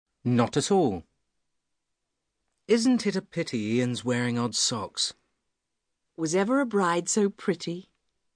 Listen to these sentences and repeat after them paying attention to the linkings.